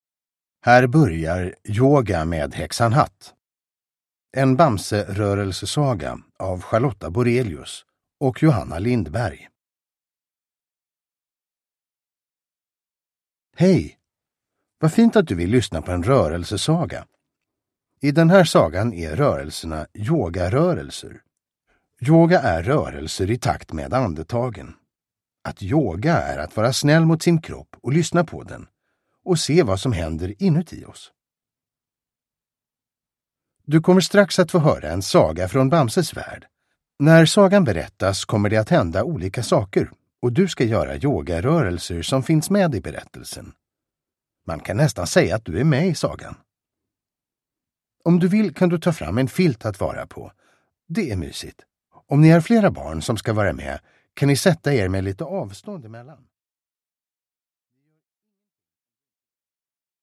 Bamse - Yoga med Häxan Hatt (ljudbok) av Charlotta Borelius